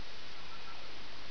File: "Vega's Starship internal rumors" (Astronave di Vega, rumori interni)
Type: Sound Effect